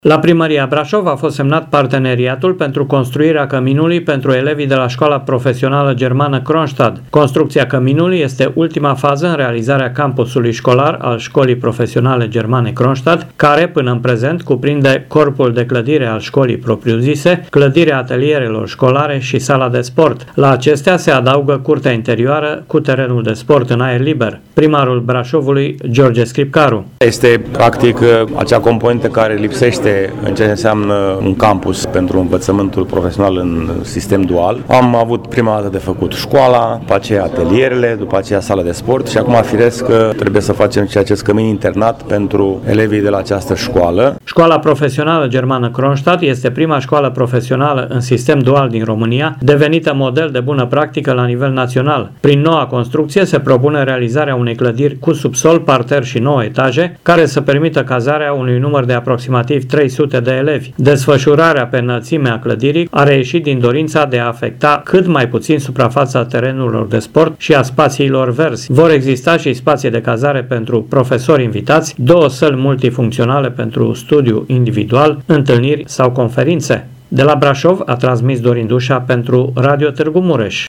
Primarul Braşovului, George Scripcaru: